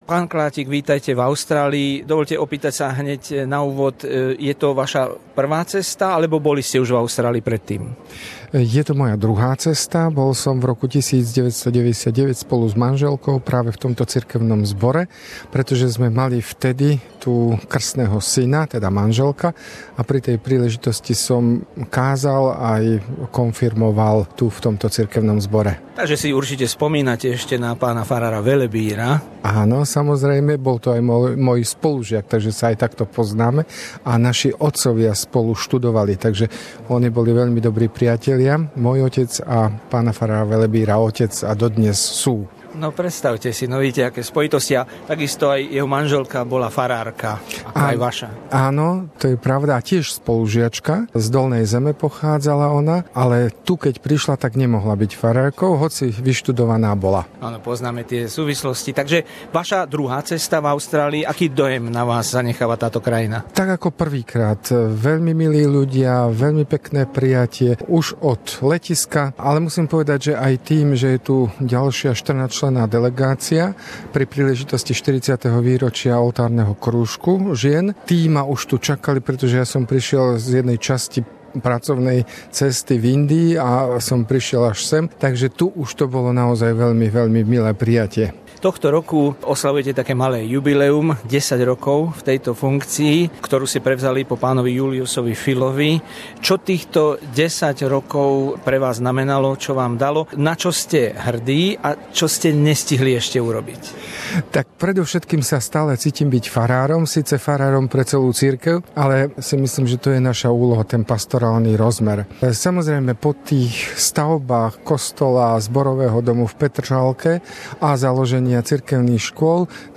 Rozhovor s generálnym biskupom Evanjelickej cirkvi na Slovensku Milošom Klátikom na jeho ceste v Melbourne